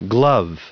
Prononciation du mot glove en anglais (fichier audio)
Prononciation du mot : glove